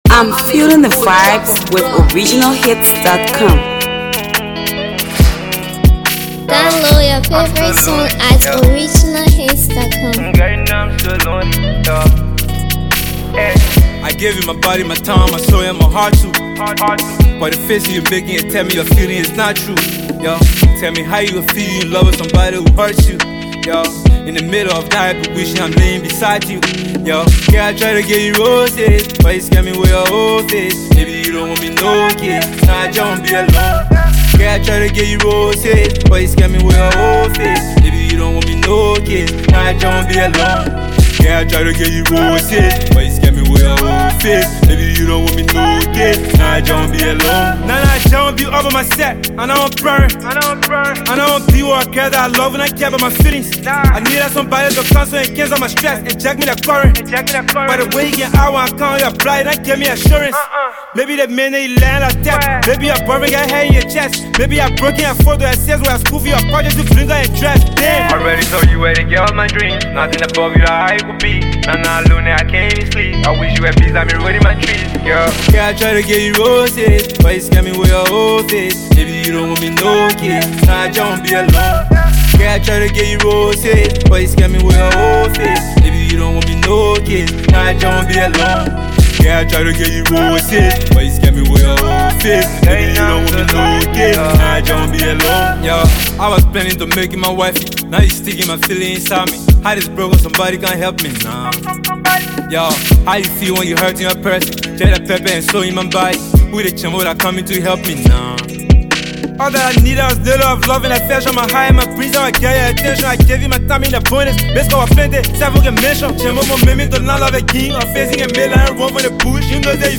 Reality rapper
mind blowing banger